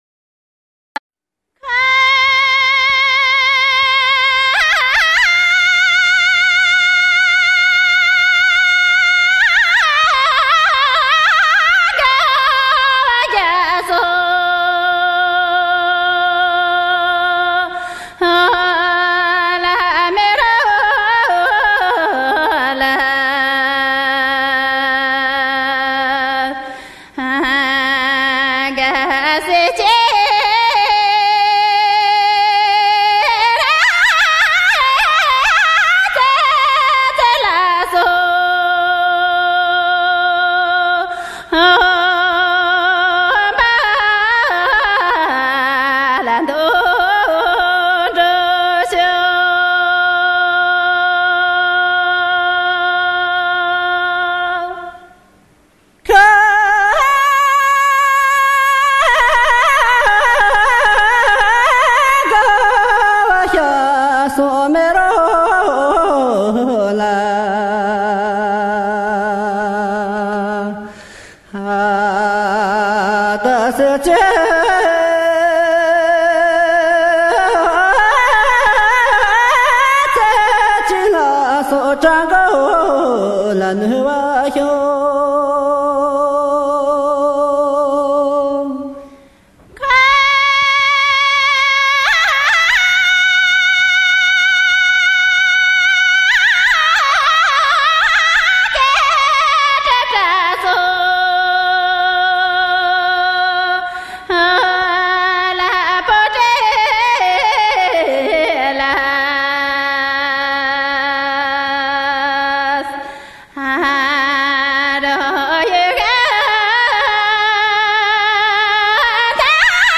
[31/7/2011]藏北 那曲 最原生态 一支牧歌 激动社区，陪你一起慢慢变老！